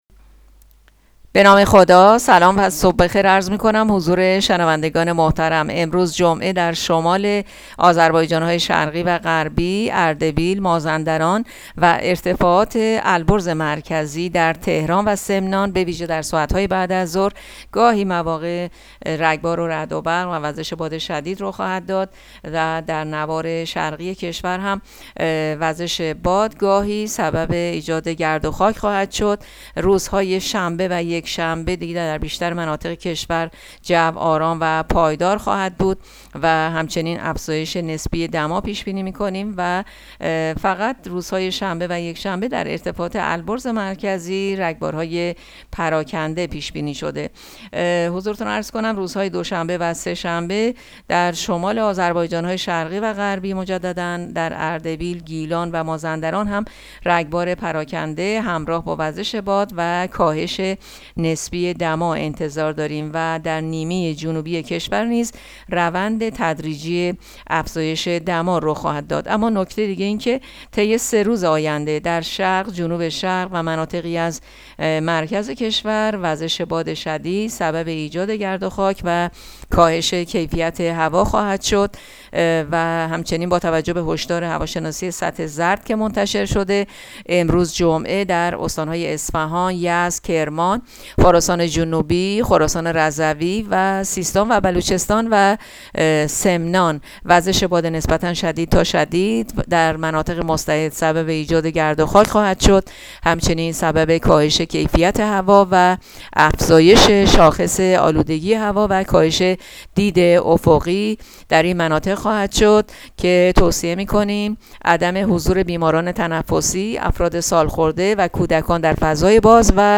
گزارش رادیو اینترنتی پایگاه‌ خبری از آخرین وضعیت آب‌وهوای ۲۳ خرداد؛